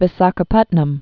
(vĭ-säkə-pŭtnəm) or Vi·sha·kha·pat·nam (-shä-) also Vi·za·ga·pa·tam (vĭ-zägə-pŭtəm)